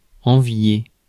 Ääntäminen
Synonyymit jalouser Ääntäminen France: IPA: [ɑ̃.vje] Haettu sana löytyi näillä lähdekielillä: ranska Käännös Verbit 1. envidiar Määritelmät Verbit Désirer pour soi les avantages d’ autrui .